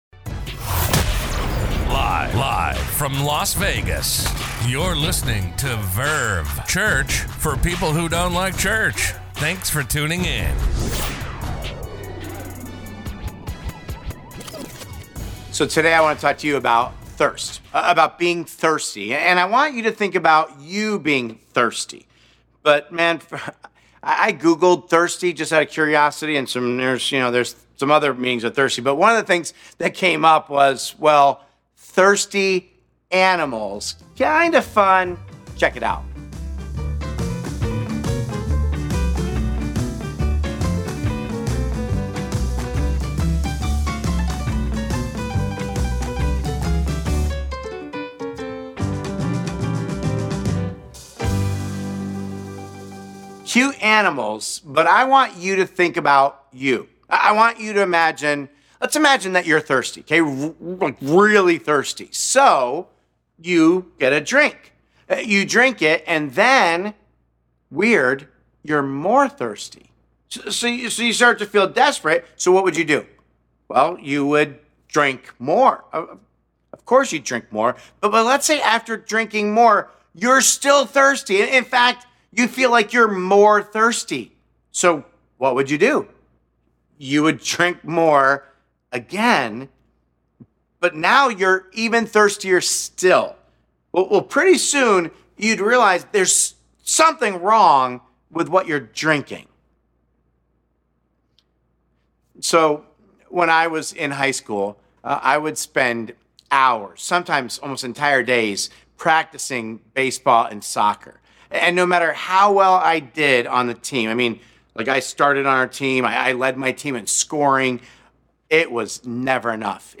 A message from the series "Not God Enough.."